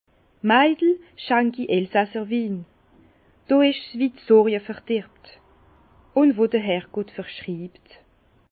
Bas Rhin
Ville Prononciation 67
Herrlisheim